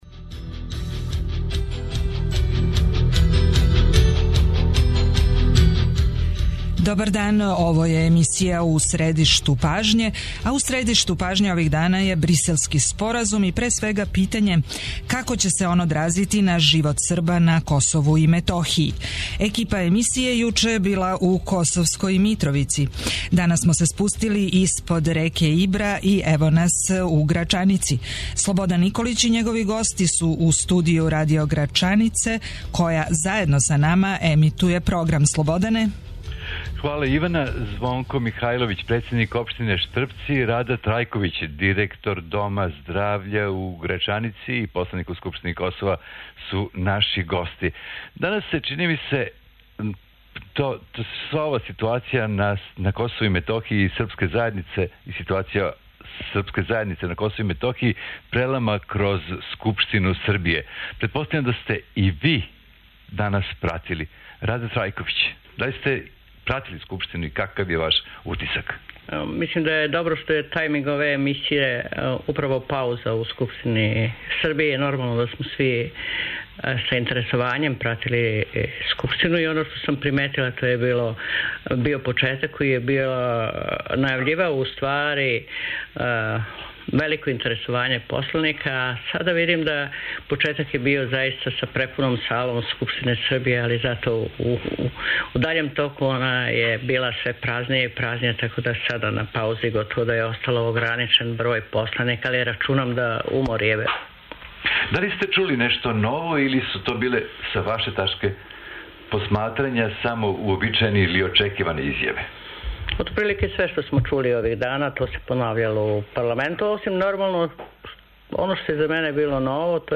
Екипа емисије У средишту пажње је на лицу места.